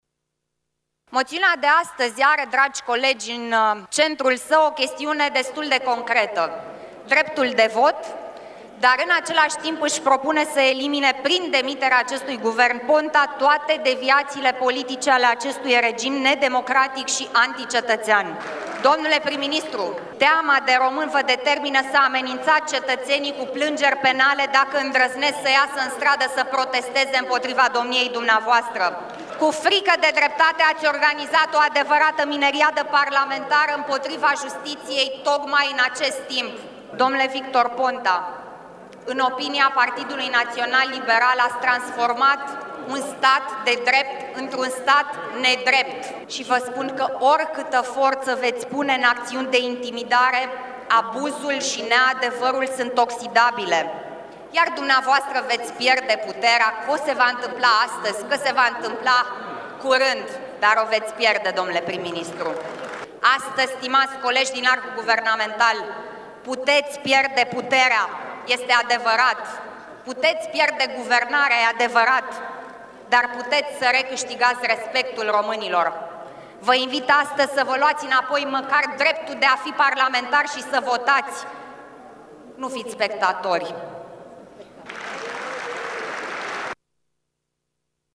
Co-președintele PNL, Alina Gorghiu, a făcut un apel la parlamentarii majoritatii sa se comporte ca niste oameni responsabili: